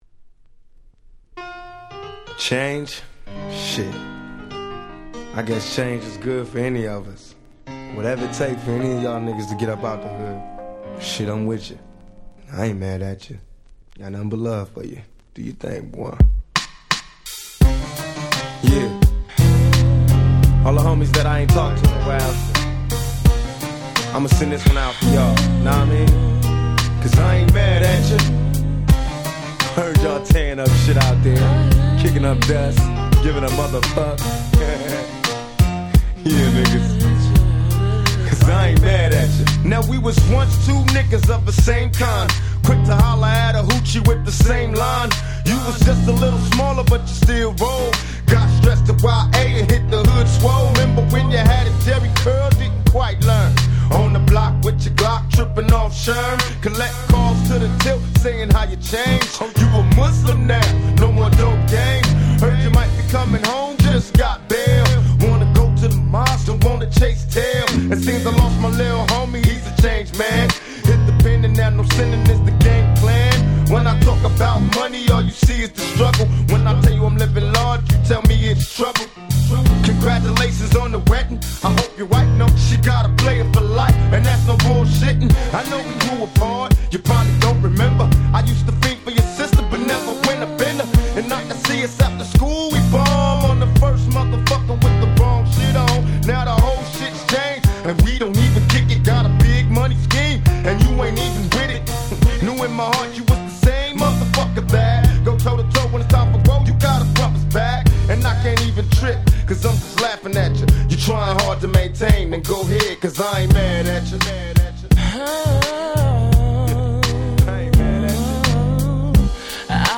96' Smash Hit West Coast Hip Hop !!
G-Rap Gangsta Rap